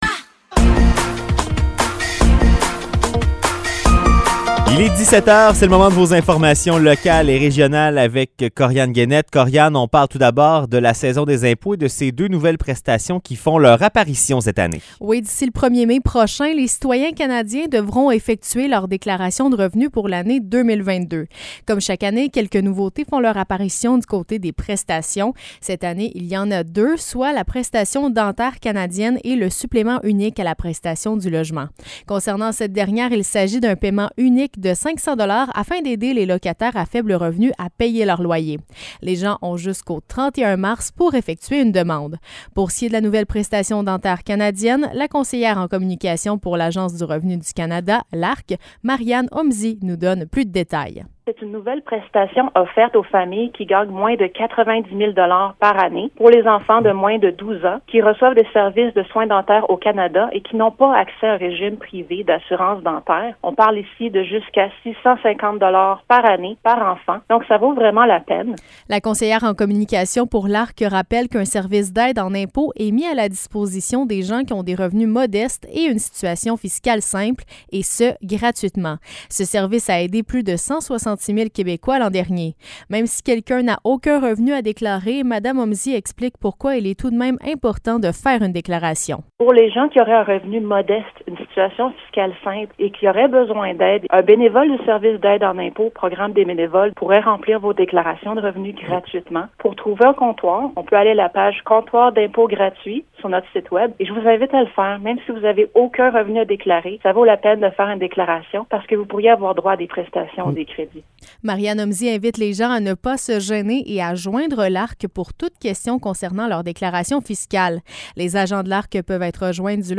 Nouvelles locales - 28 février 2023 - 17 h